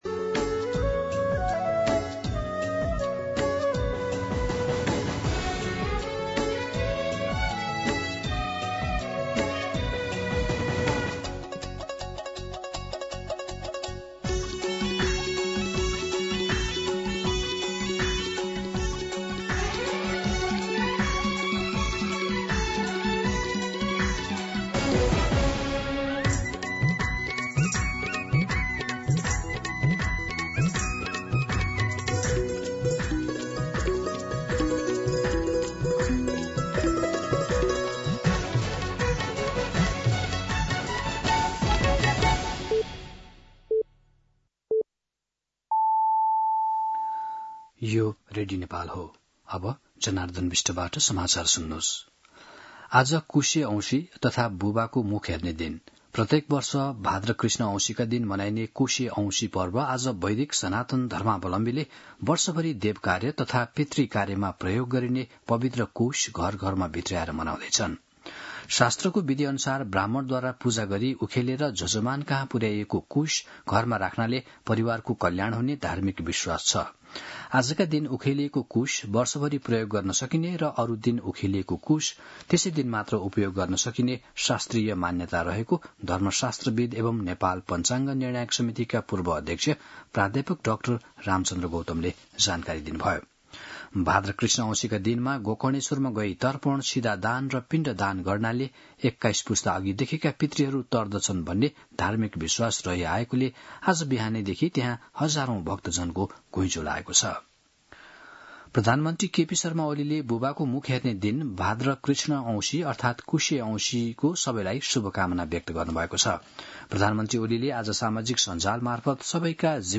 दिउँसो १ बजेको नेपाली समाचार : ७ भदौ , २०८२
1-pm-Nepali-News-6.mp3